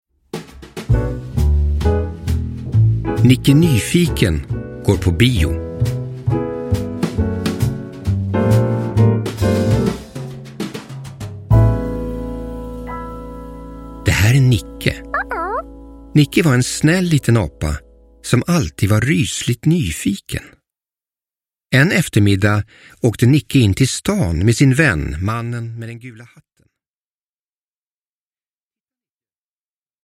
Nicke Nyfiken går på bio – Ljudbok – Laddas ner